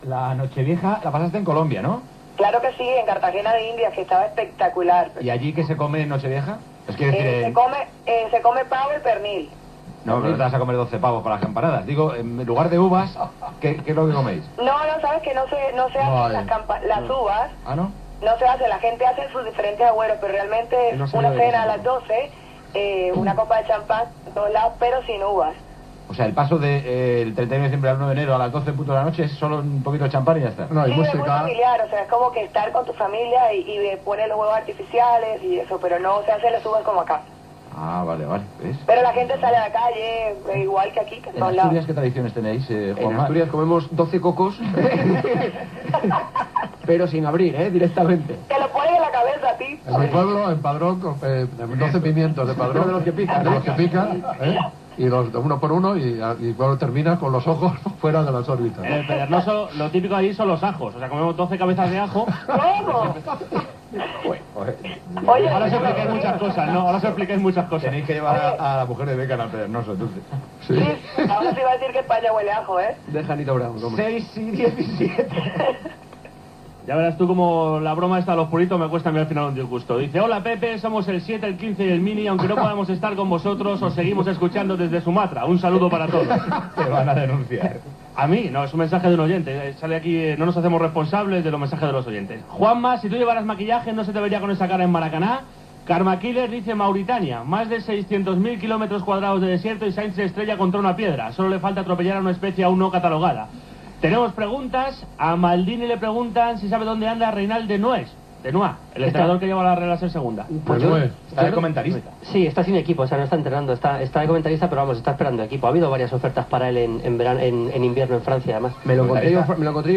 Com es passa el cap d'any a Colòmbia i en alguns llocs d'Espanya. Hora, missatges de l'audiència i comentaris de l'equip. Trucada al Ministeri de Sanitat i Consum espanyol per saber si encara es pot fer publicitat de "Puritos Reig"
Esportiu